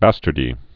(băstər-dē)